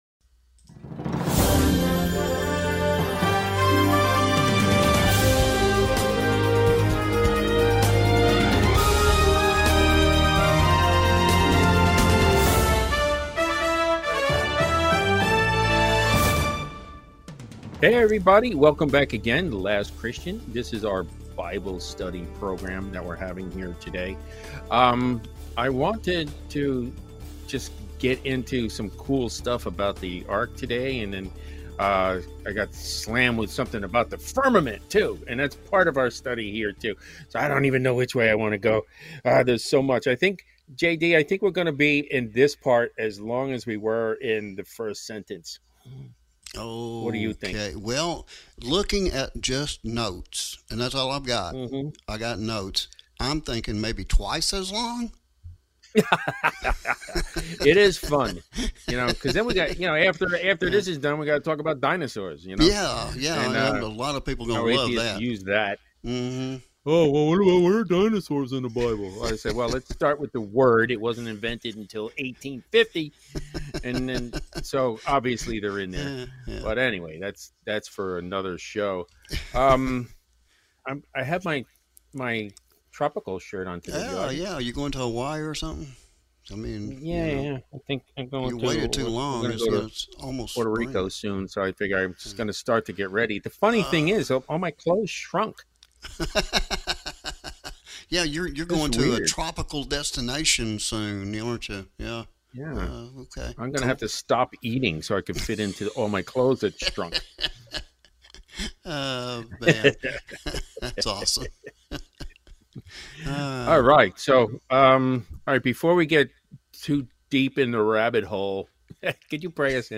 We invite you you join these two Members of the US Press Association as they dive deep into Scripture.